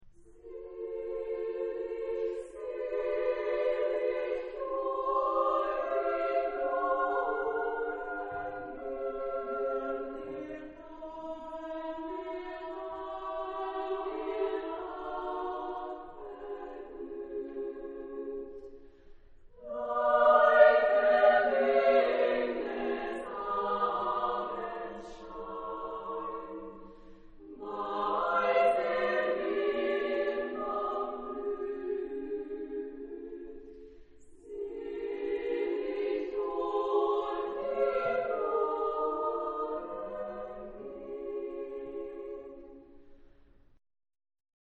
Genre-Style-Forme : Pièce chorale ; Profane
Type de choeur : SSAA  (4 voix égales de femmes )
Tonalité : fa majeur
Réf. discographique : Internationaler Kammerchor Wettbewerb Marktoberdorf